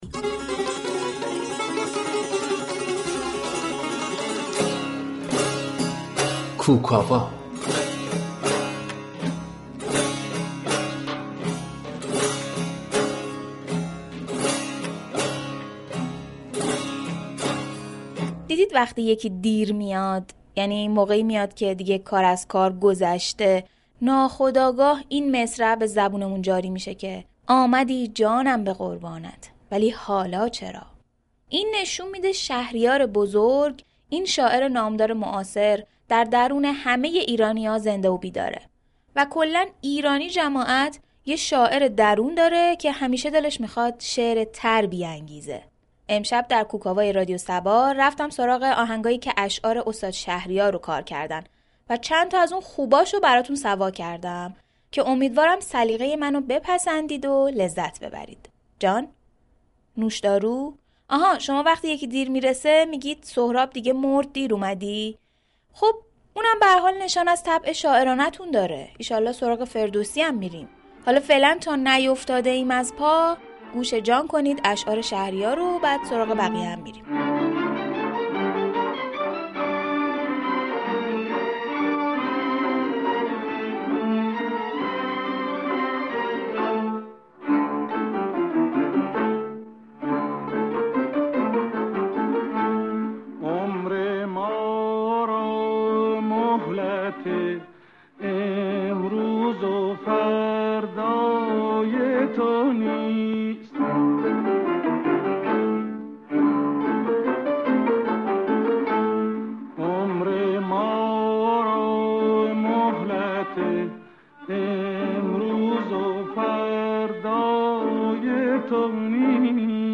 «كوك آوا» یكی از برنامه‌های موسیقی محور این شبكه رادیویی است، كه با تركیب متن، ترانه و موسیقی بسته شنیداری جالبی را هر شب تقدیم شنوندگان می كند.
این برنامه در هر قسمت با انتخاب یك وجه مشترك، ترانه و موسیقی را به هم كوك می زند، و چهارشنبه اشعار شهریار این برنامه را كوك می كند.